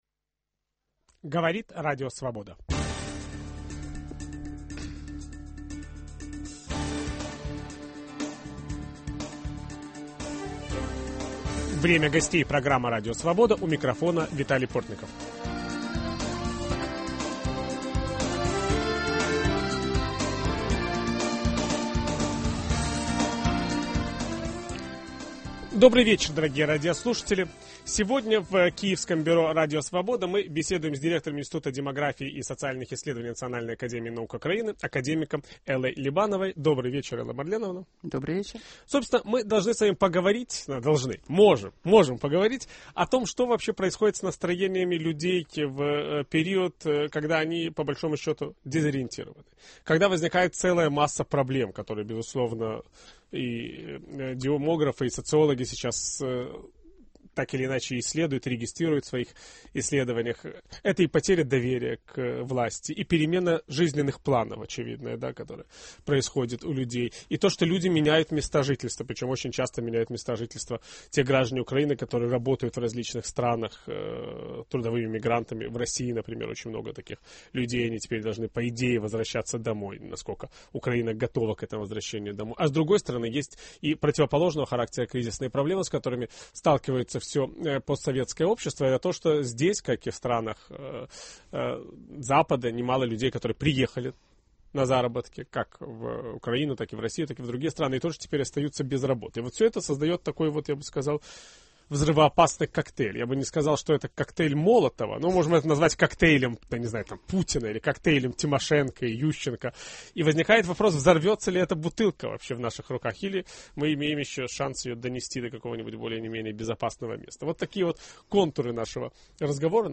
Ведущий программы Виталий Портников беседует об этом с директором Института демографии и социальных исследований Национальной академии наук Украины академиком Эллой Либановой